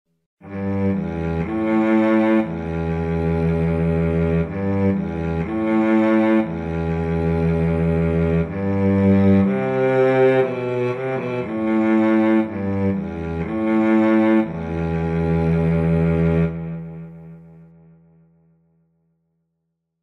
volgacello.mp3